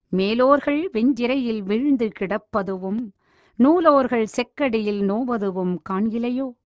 என்று பாரதியார் அயலவர் ஆட்சிக் கொடுமையைப் பாடுகிறார்.